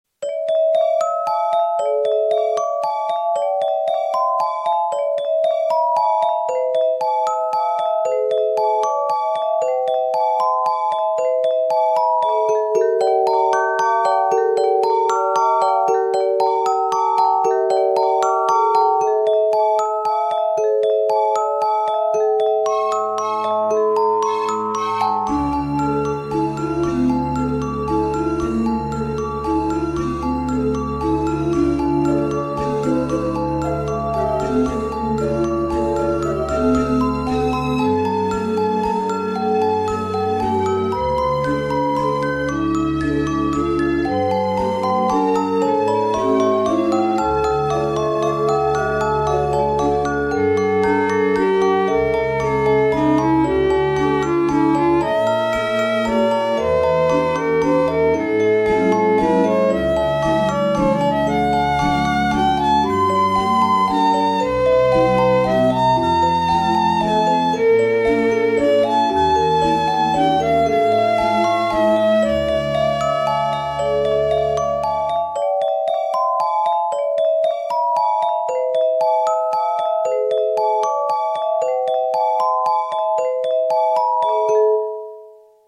ナオコでいろんなMIDIを鳴らしてみた。
一部の楽器はすごくいい音が鳴るが、
それ以外は妙にチープだったり、そもそも鳴らなかったりする。